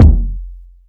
KICK.19.NEPT.wav